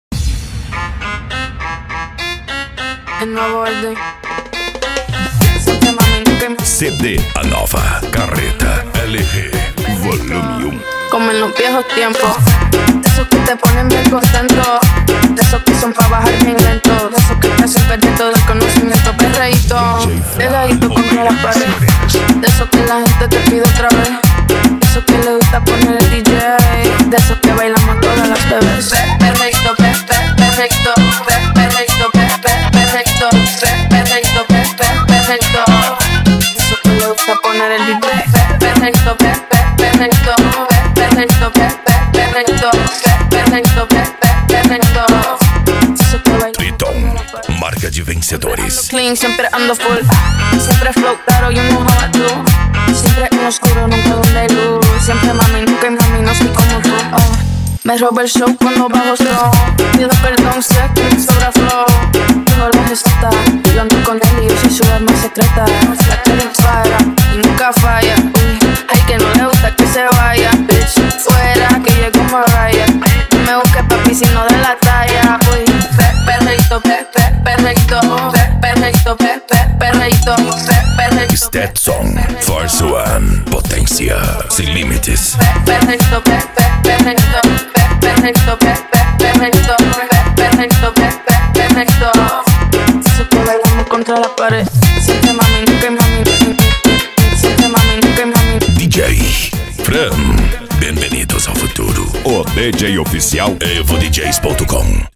Remix
Variados